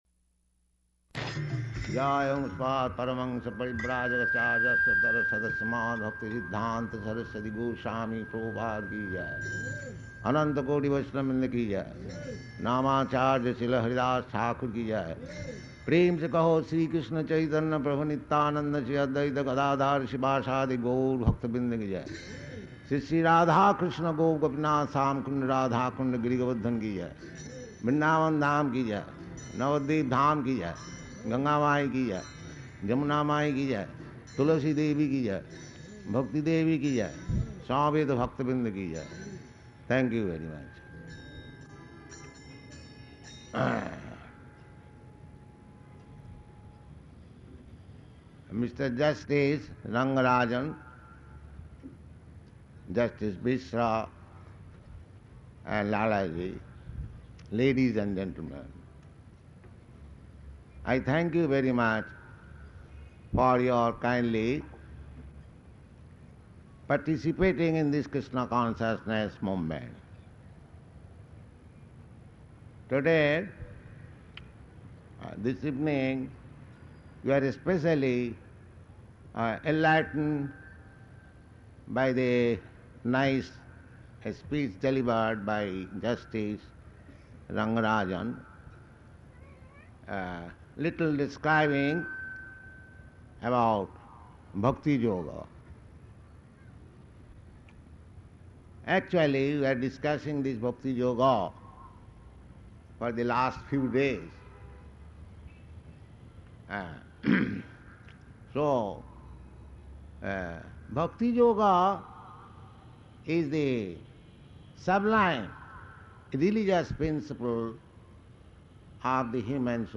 Paṇḍāl Lecture
Type: Lectures and Addresses
Location: Delhi